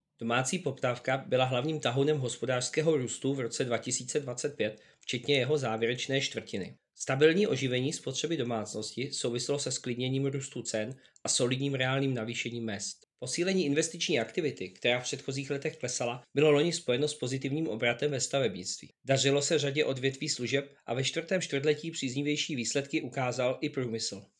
Vyjádření Jaroslava Sixty, místopředsedy Českého statistického úřadu, soubor ve formátu MP3, 955.31 kB